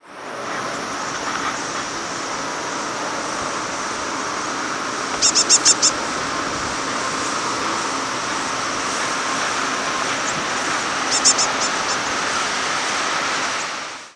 Blue-gray Gnatcatcher Polioptila caerulea
Flight call description A soft, mewing "bzew-bzew-bzew" (typically two to five notes).
Diurnal calling sequences: